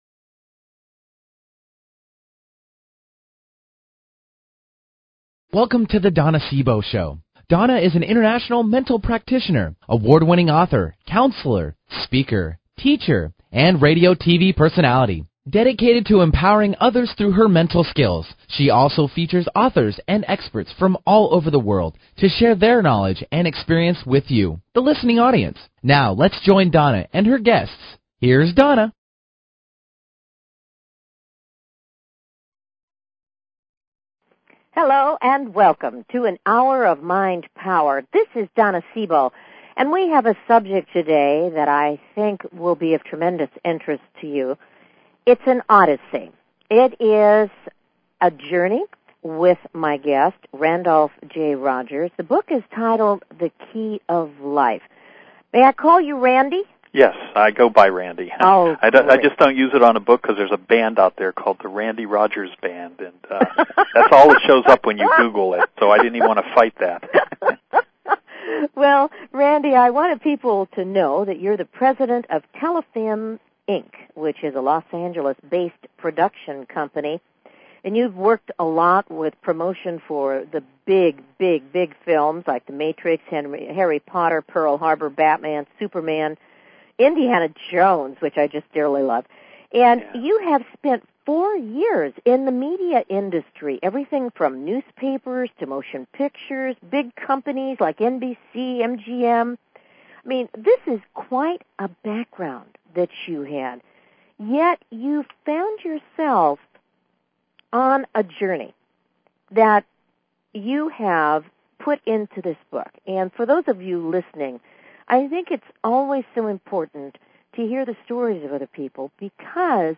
Talk Show Episode
Callers are welcome to call in for a live on air psychic reading during the second half hour of each show.